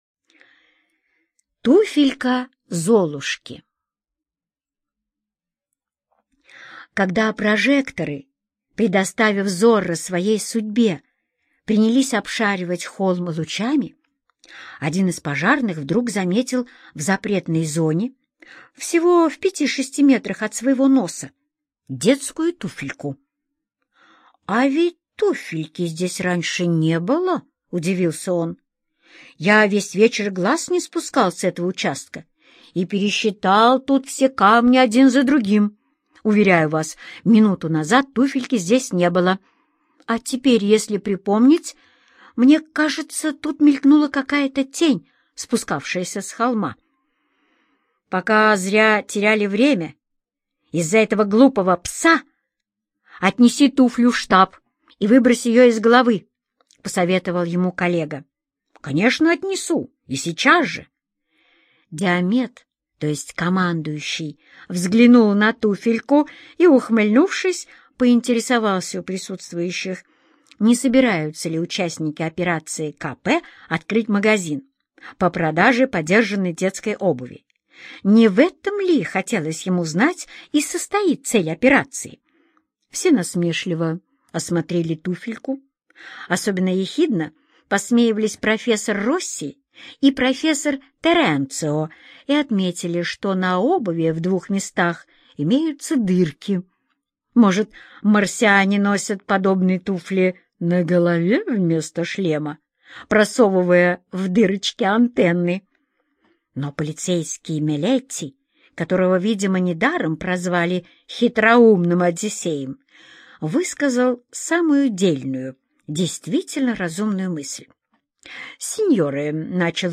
Туфелька Золушки - аудиосказку Родари Д. Про то, как полицейский увидел на земле туфельку, которой тут не было, и начал расследование.